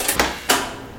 reactorStart.ogg